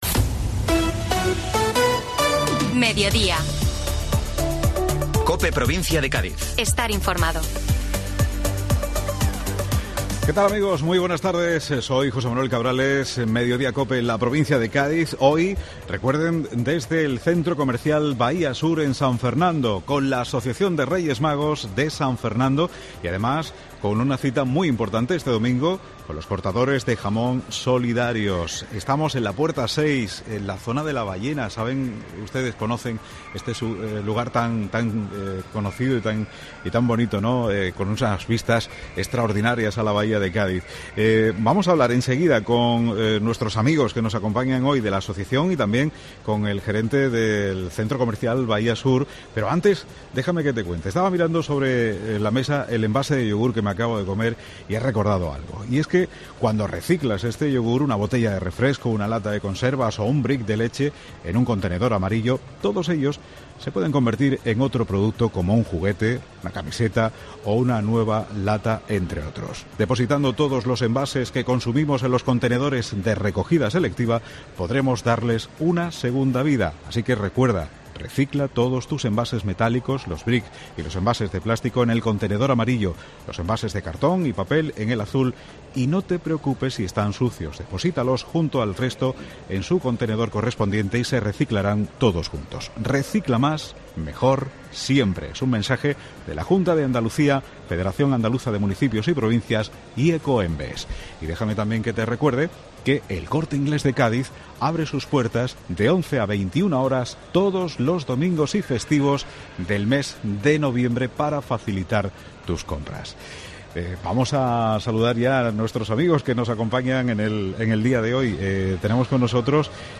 Herrera en COPE y Mediodía COPE Provincia de Cádiz desde Centro Comercial Bahía Sur con la Asociación de Reyes Magos de San Fernando con motivo de la Feria Solidaria de Cortadores de Jamón que reunirá este domingo 6 de noviembre a partir de las 12:00h a más 200 cortadores profesionales en la Avenida Almirante León Herrero de San Fernando.